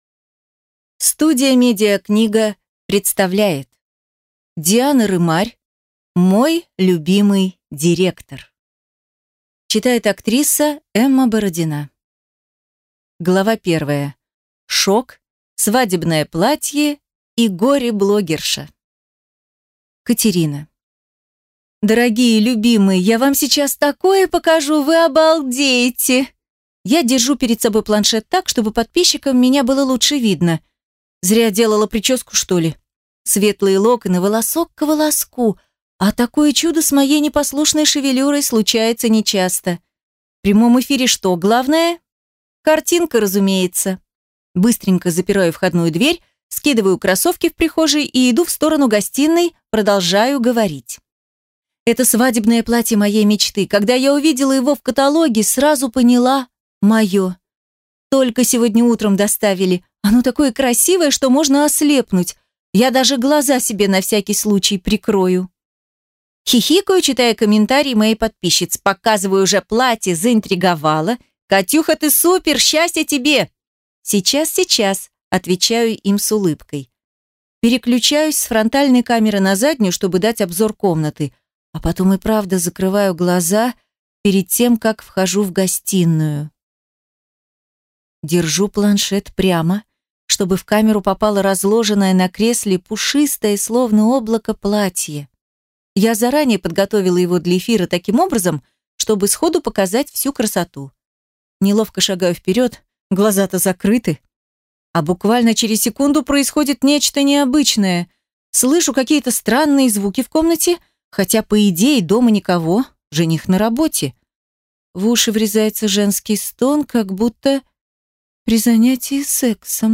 Аудиокнига Мой любимый директор | Библиотека аудиокниг
Прослушать и бесплатно скачать фрагмент аудиокниги